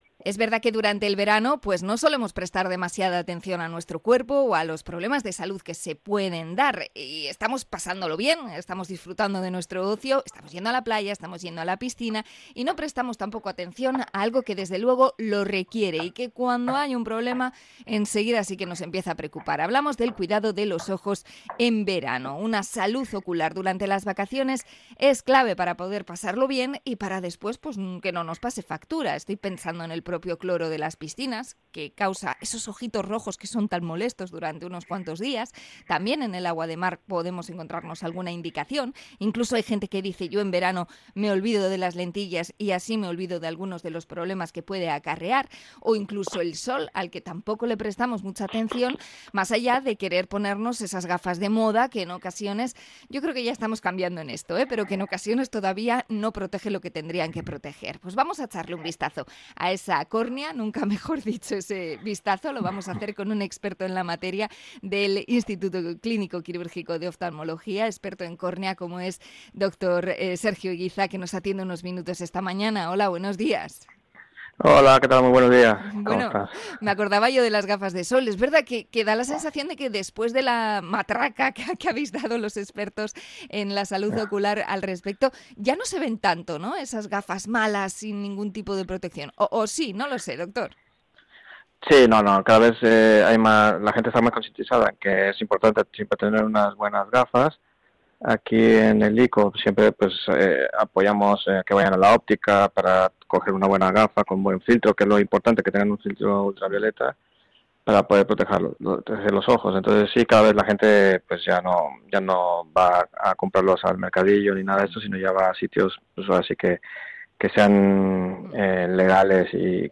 Entrevista a oftalmólogo